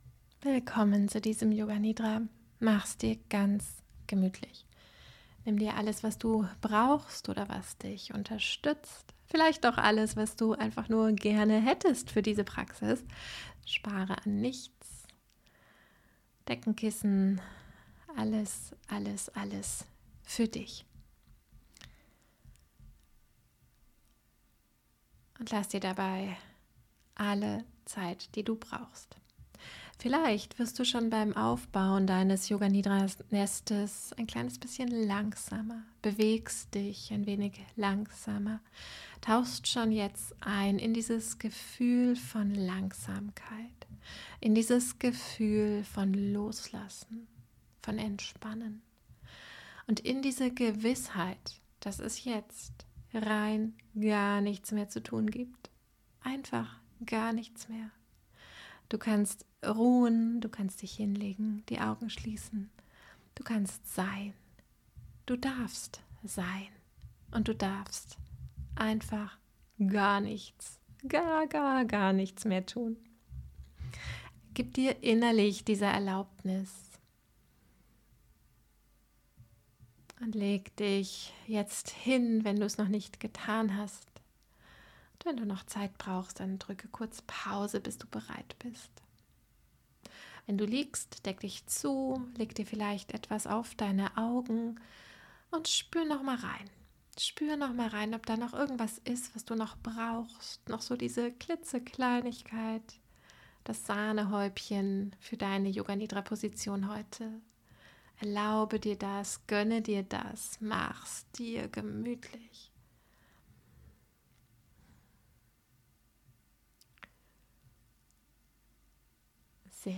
Vocal Mode: Spoken word